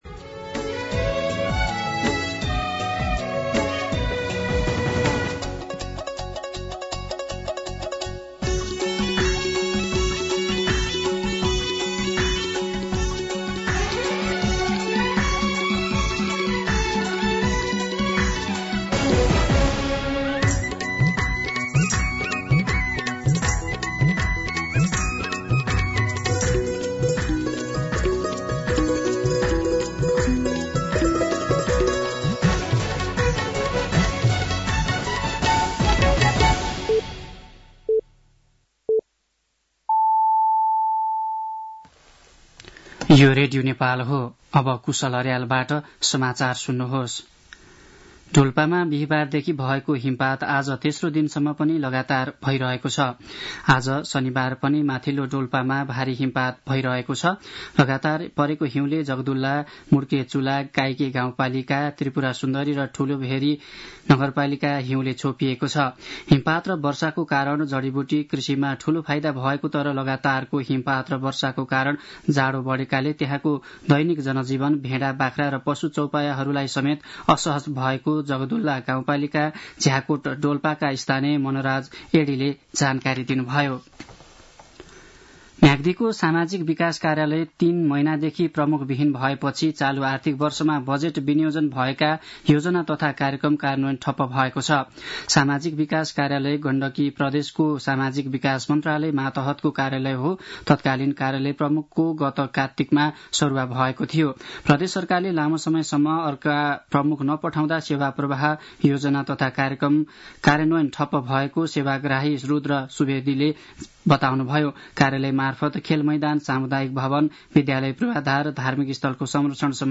दिउँसो १ बजेको नेपाली समाचार : १८ फागुन , २०८१